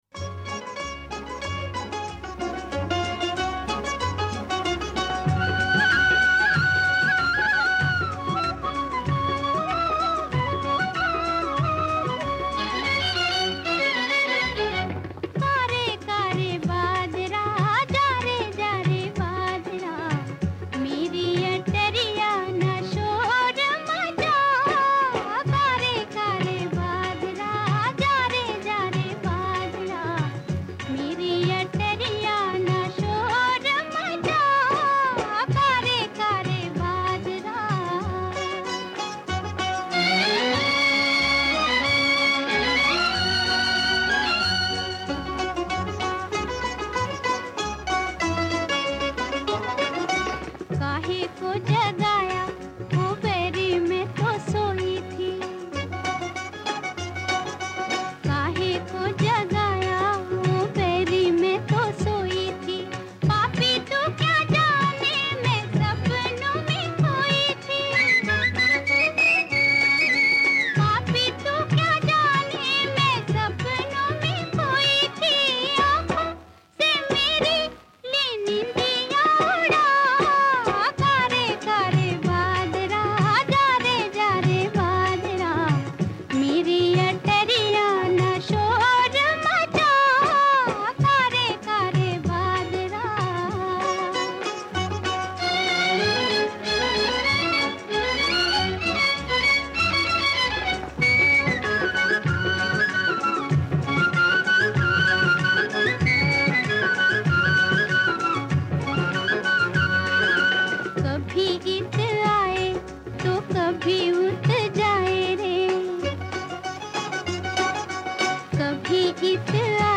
‘वृदांवनी सारंग’ राग आणि ‘केहारवा’ तालात हे गाणे बांधले आहे.